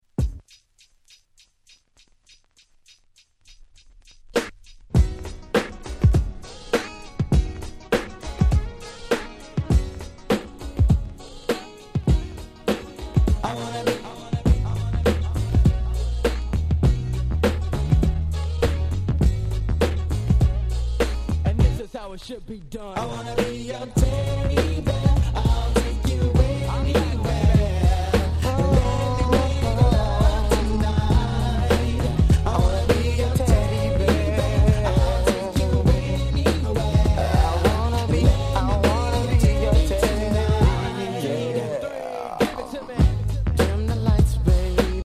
※試聴ファイルは別の盤から録音してございます。
超絶人気New Jack Swing !!!!!